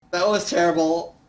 Tags: Soundboard angry gamer